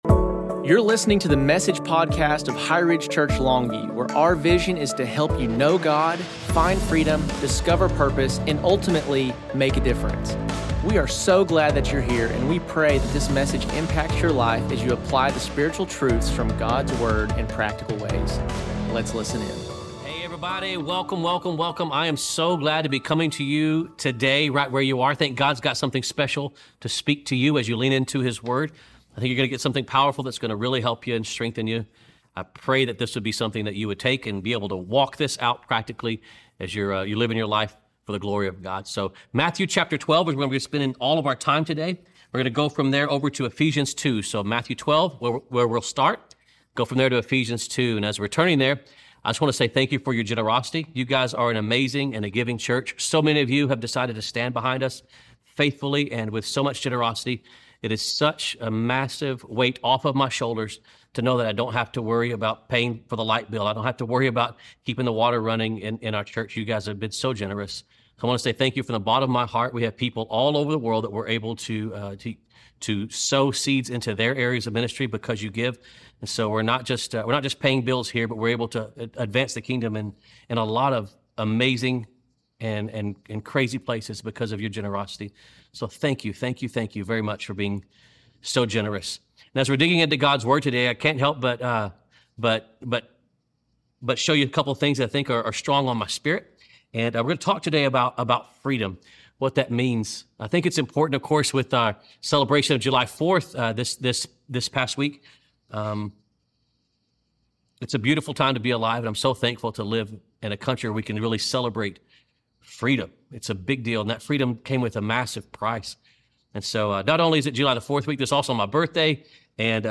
Message: Jesus the Storyteller (Freedom)